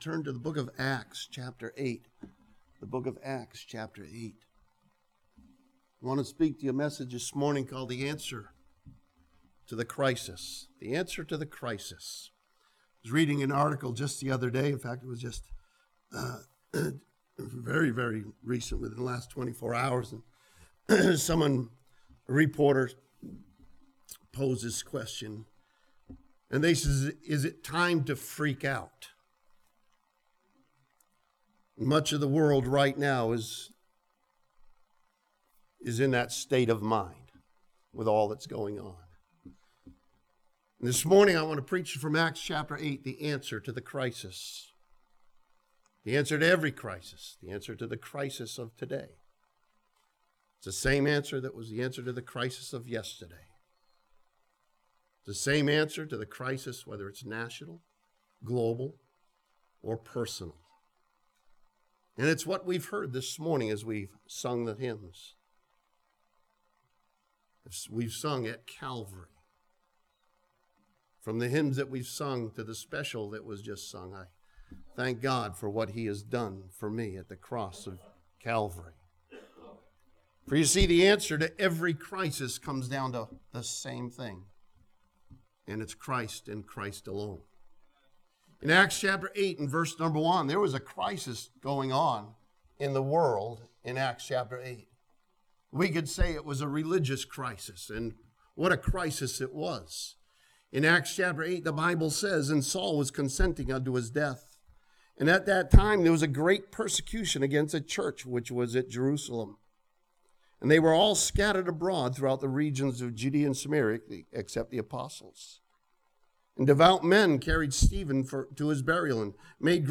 This sermon from Acts chapter 8 teaches believers that the answer to the crisis is always Jesus Christ.